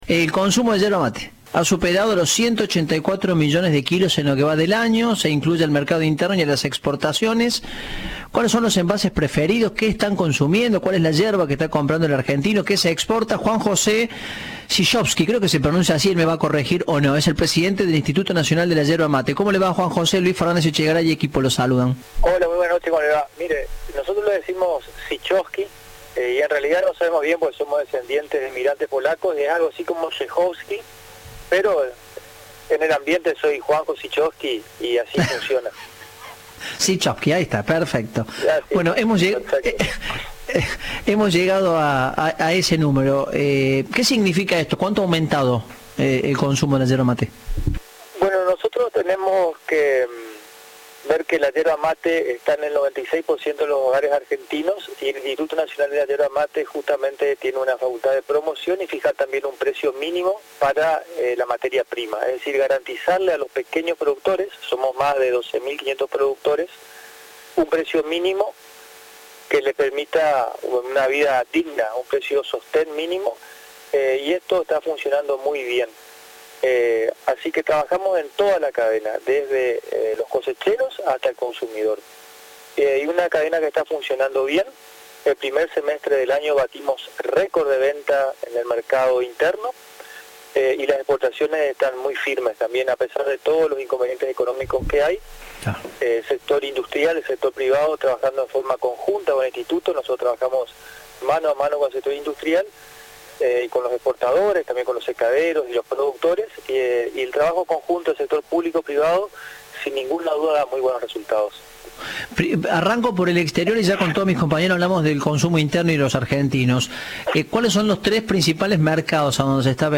Entrevista de “Informados al Regreso”.